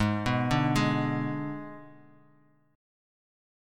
G#dim chord